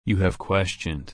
/ˈkwɛst͡ʃən/